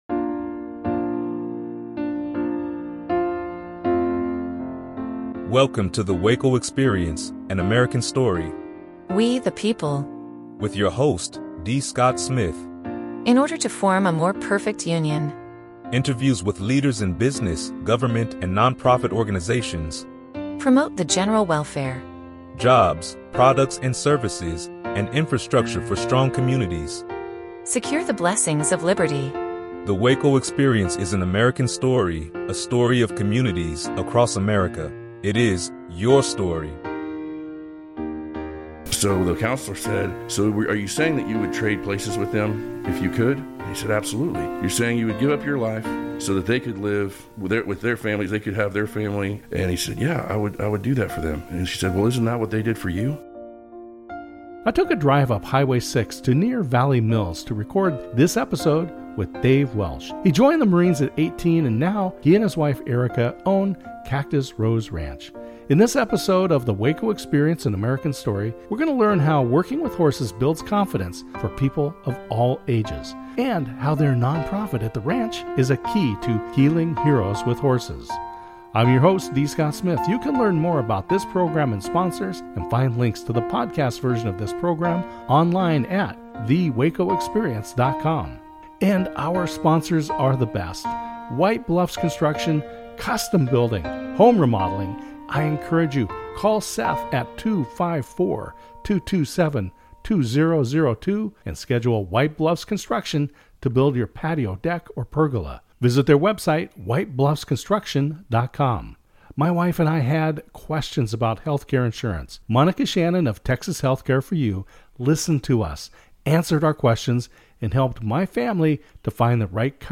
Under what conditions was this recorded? Runtime: 28 minutes, 30 seconds Originally aired on KBBW Radio at 8:30 AM, Saturday, April 11th, 2026.